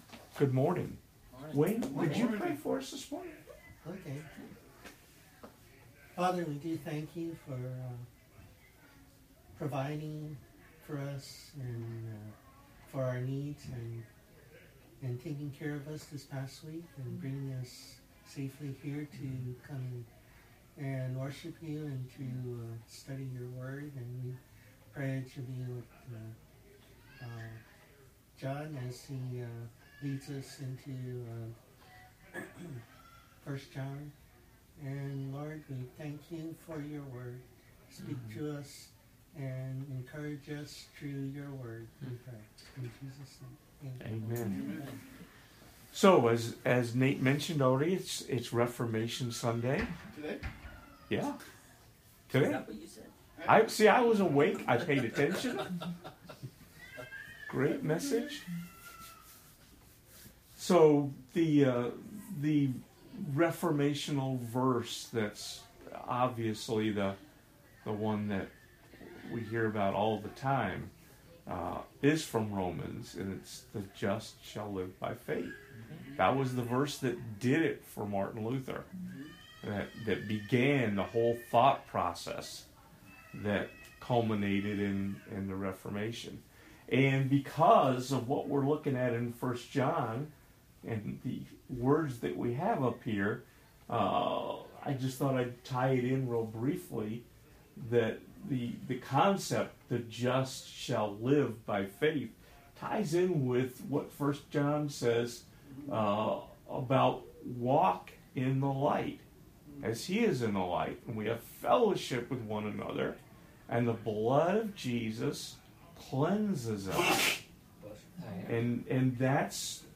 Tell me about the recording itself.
This week we continue to look at 1 John 1:9. This post contains everything from week 22 of the Adult CE class at Eastside Community Church in Jacksonville, FL.